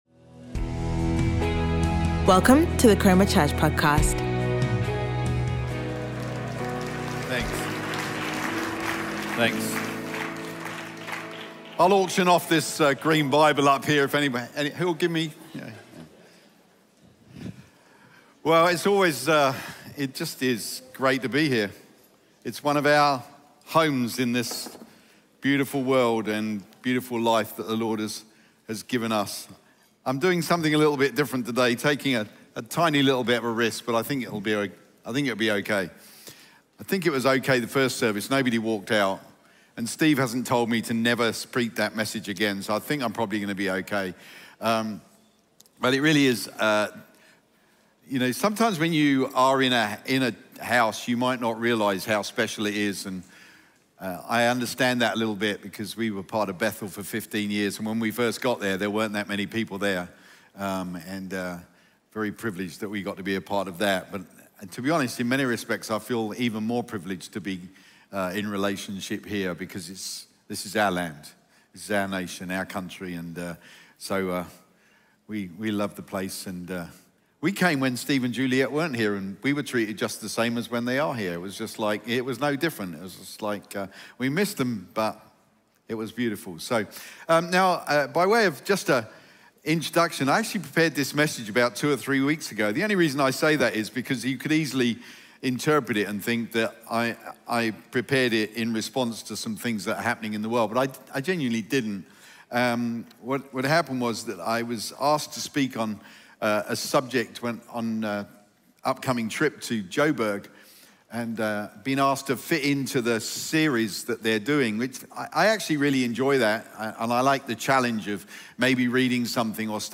Chroma Church - Sunday Sermon Restoring the Creator's Narrative